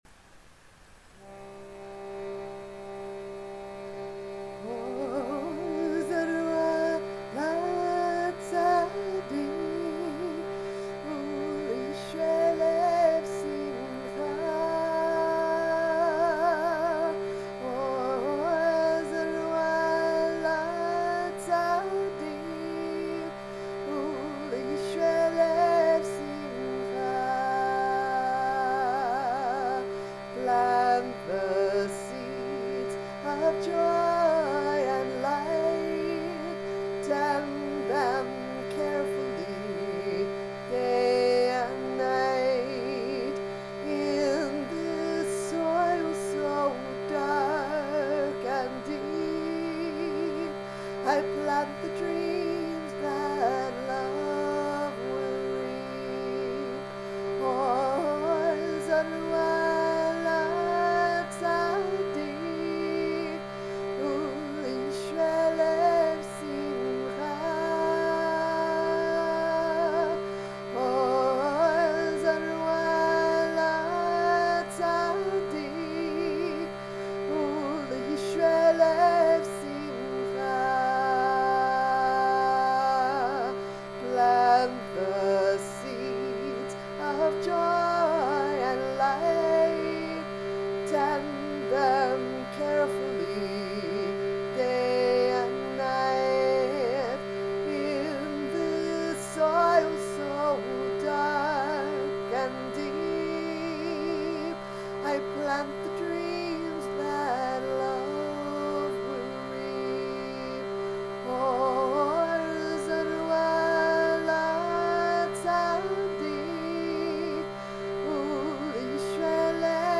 Chants, Psalms